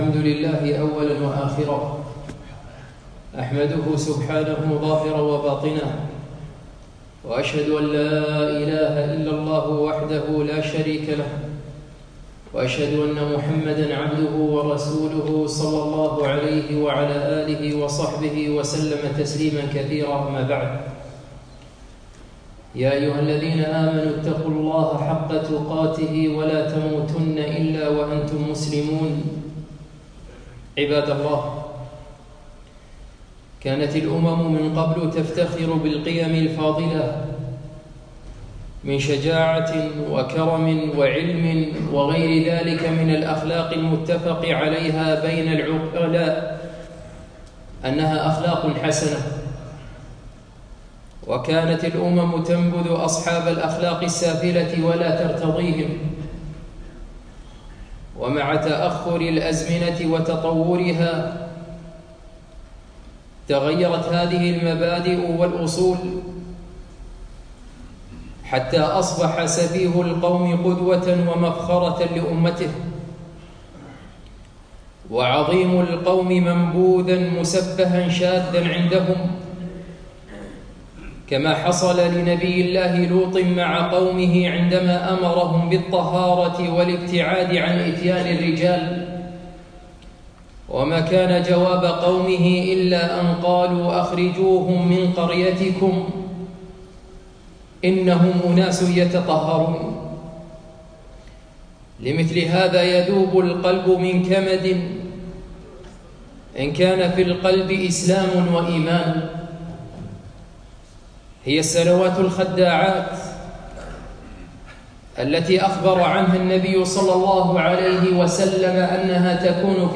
خطبة - سنوات خداعات - دروس الكويت